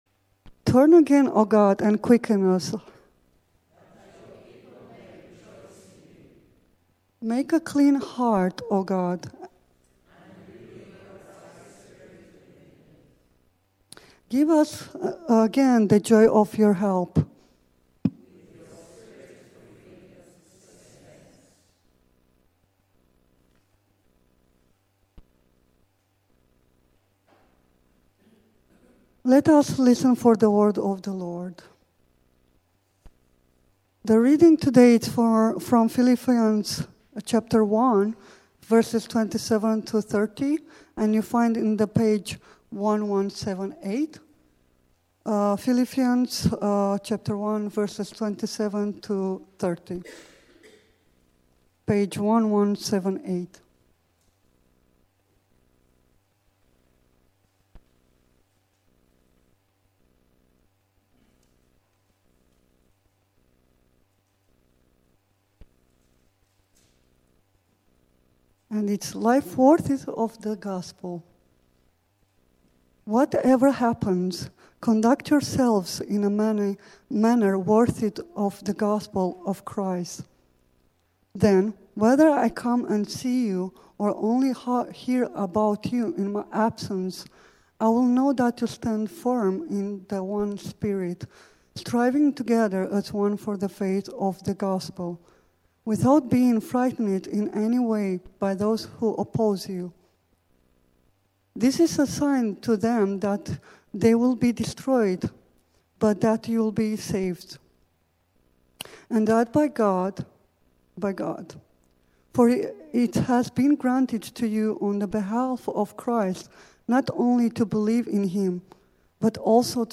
Morning Prayer